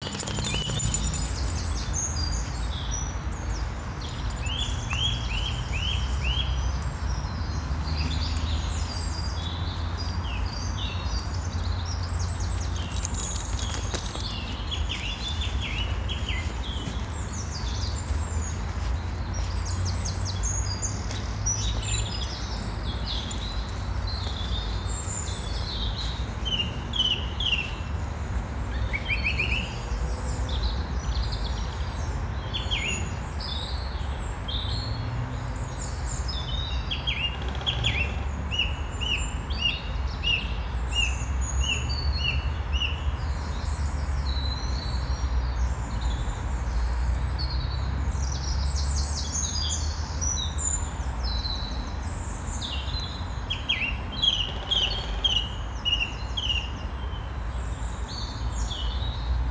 Morning Bird Song
The birds were in particularly fine voice this morning, so I recorded a little bit of it for you. Can you hear the woodpecker?
morning-bird-song.wav